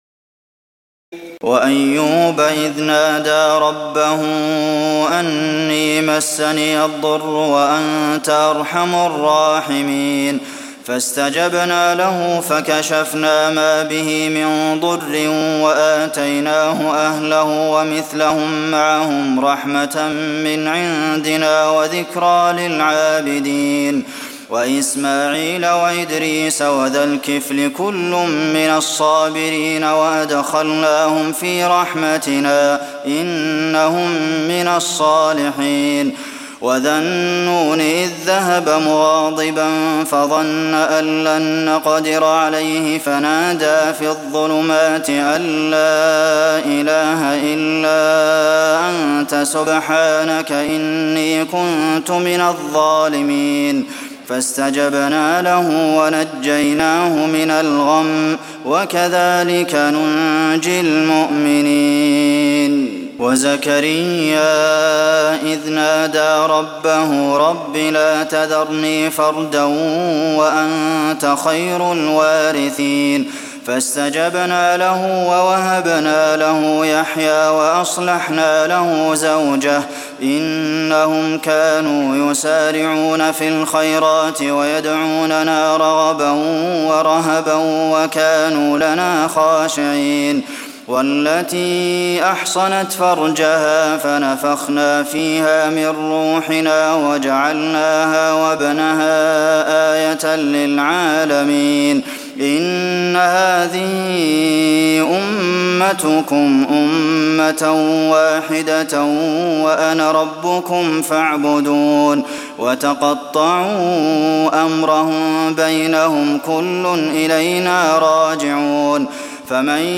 تراويح الليلة السادسة عشر رمضان 1423هـ من سورتي الأنبياء (83-112) الحج (1-37) Taraweeh 16 st night Ramadan 1423H from Surah Al-Anbiyaa and Al-Hajj > تراويح الحرم النبوي عام 1423 🕌 > التراويح - تلاوات الحرمين